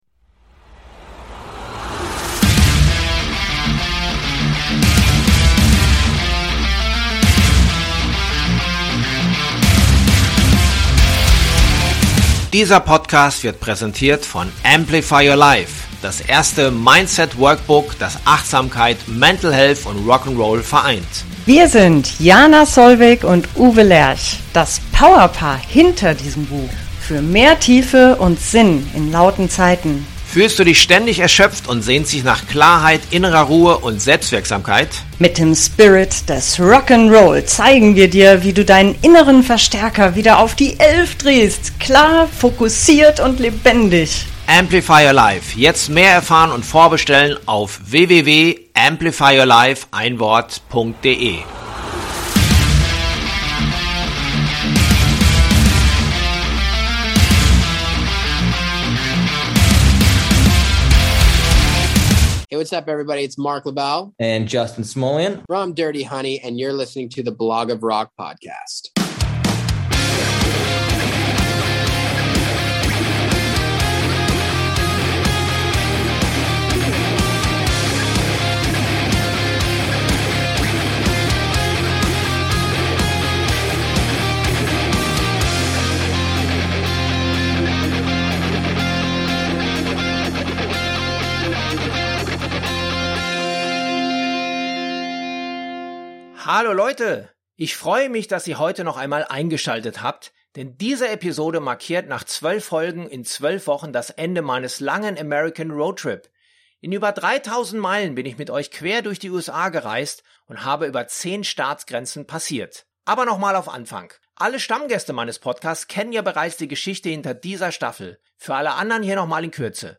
Bevor sie mit den BLACK CROWES aus große Nordamerika-Tour gehen, erwischte ich Ausnahmesänger